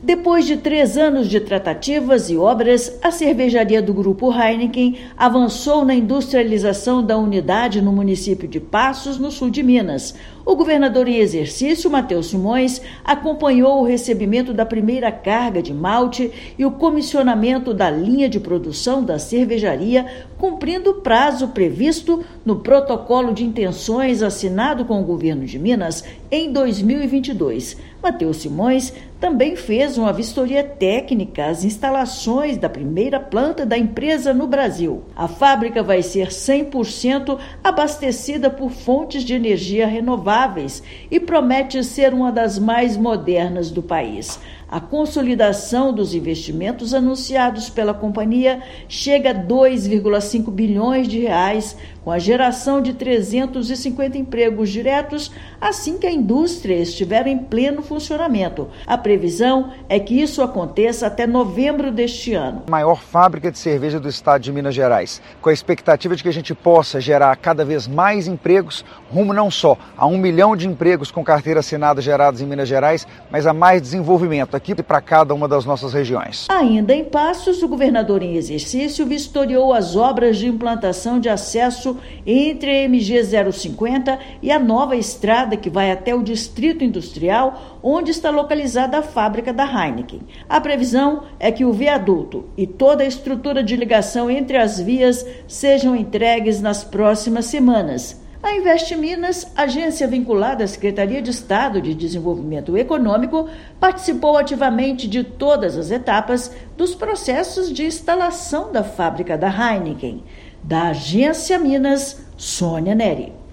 [RÁDIO] Governo de Minas acompanha chegada dos primeiros insumos à cervejaria Heineken em Passos
Início do comissionamento da linha de produção marca nova etapa de projeto que envolve R$ 2,5 bilhões em investimentos e que vai gerar 350 empregos diretos. Ouça matéria de rádio.